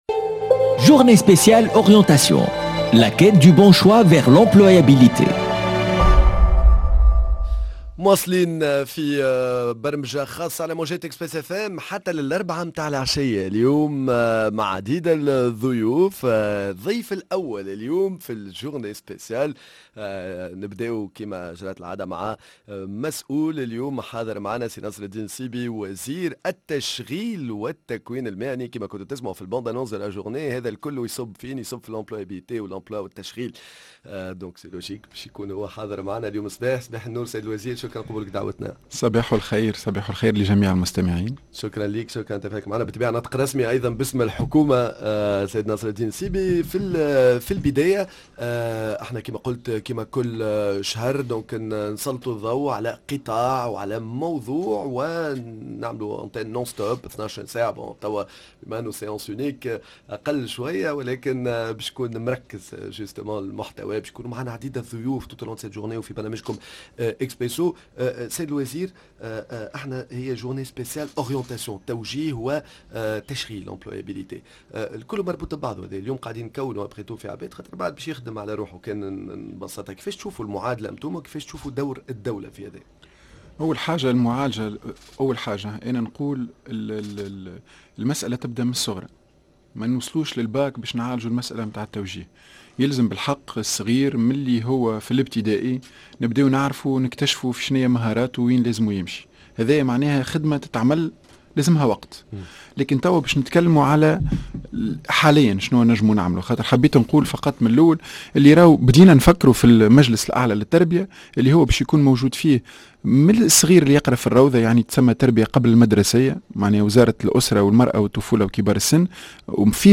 فاش تتمثل استراتيجية الدولة المتعلقة بالتوجيه الجامعي وعلاقتها بالتشغيل، ضيفنا الناطق الرسمي باسم الحكومة ووزير التكوين المهني والتشغيل نصر الدين النصيبي.